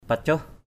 /pa-coh/ (t.) nhừ = se détacher en morceaux (après longue cuisson). riak abu ka pacoh r`K ab~% k% p_cH nấu cháo cho nhừ.
pacoh.mp3